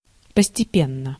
Ääntäminen
IPA : /ˈpiːs.miːl/